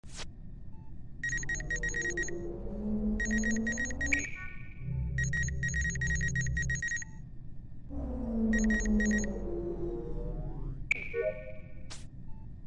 Among Us Medbay Scan Sound Effect Free Download